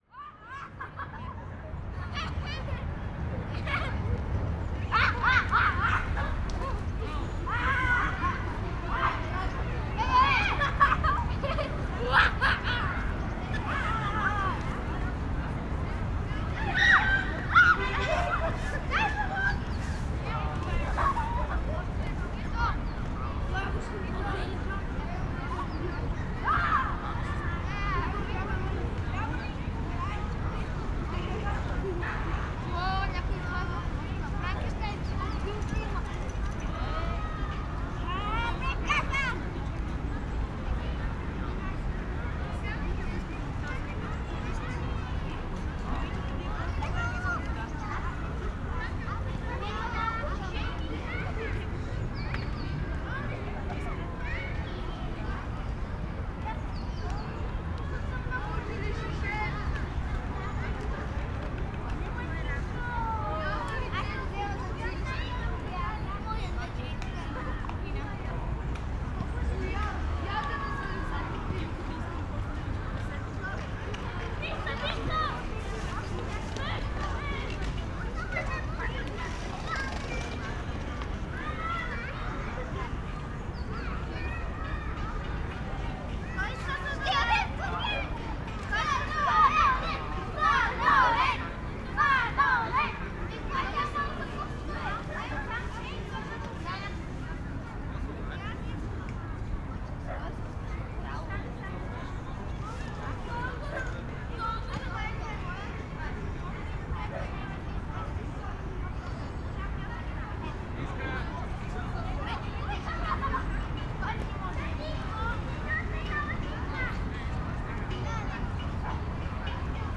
ambience
City Park Ambience - Crowd 4 Kids